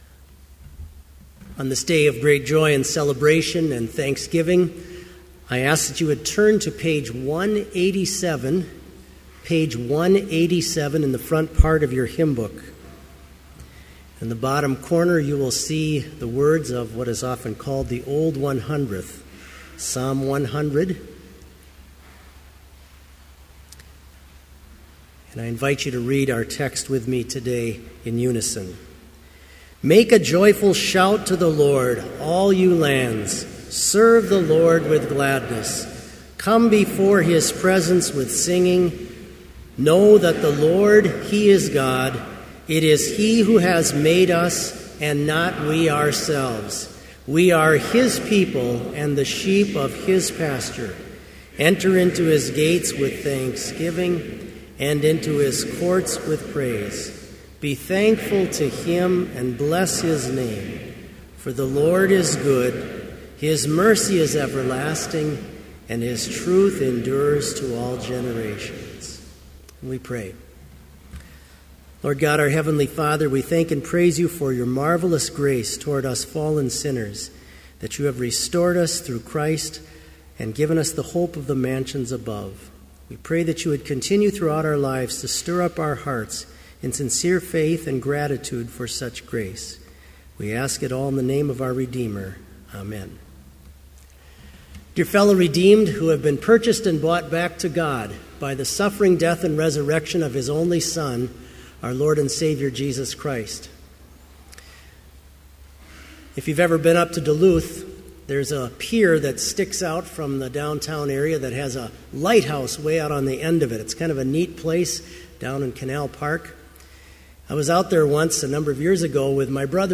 Sermon audio for Evening Vespers - May 7, 2014